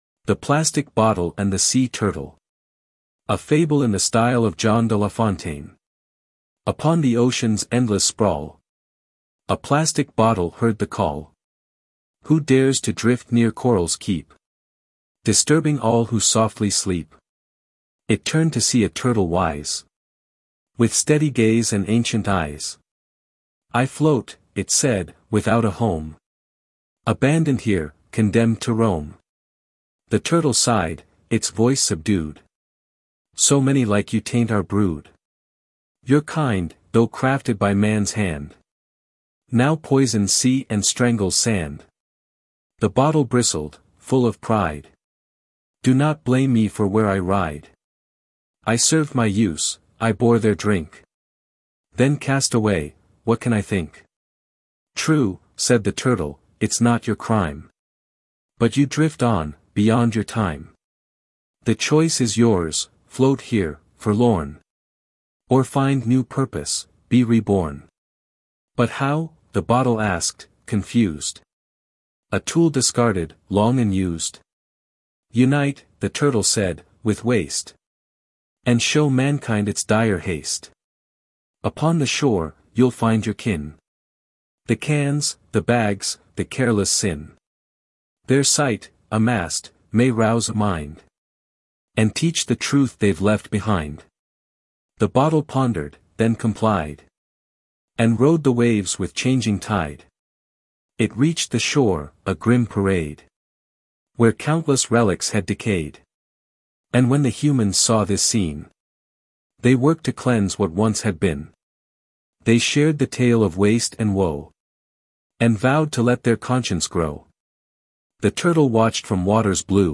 Turtle and the Plastic Bottle - Fable